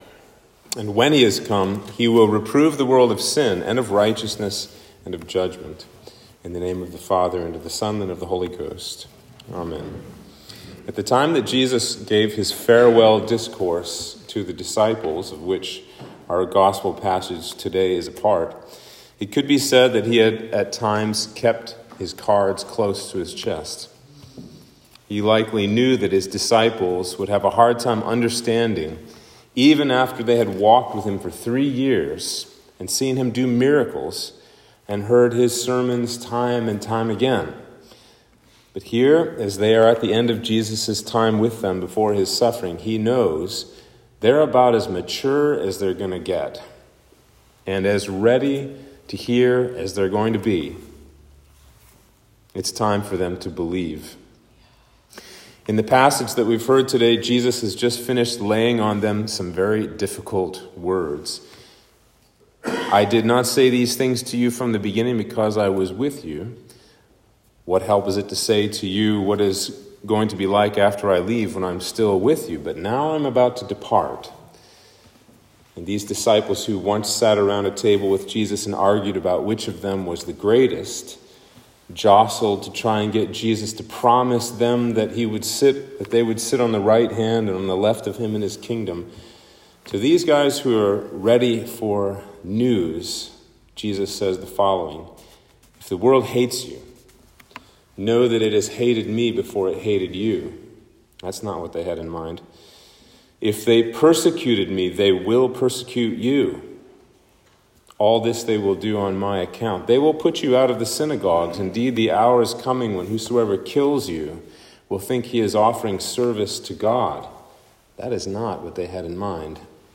Sermon for Easter 4